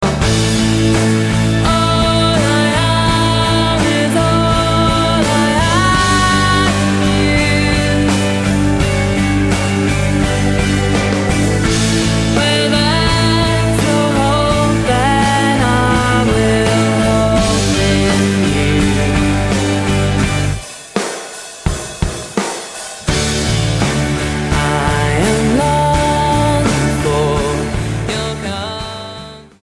Leeds-based radical worship collective
Style: Ambient/Meditational Approach: Praise & Worship